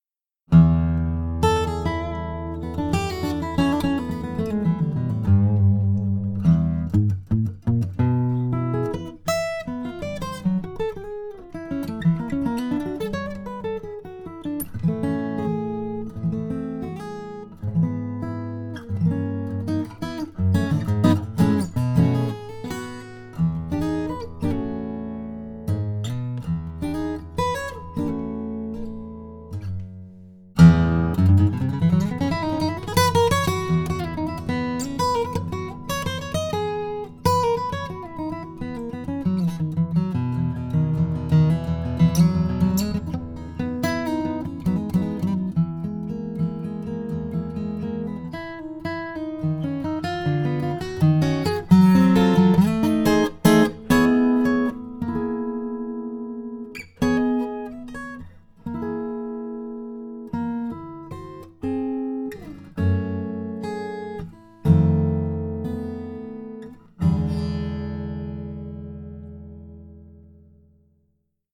Akustická kytara v modelovém provedení Dreadnought 28 je vyrobena z celomasivního dřeva.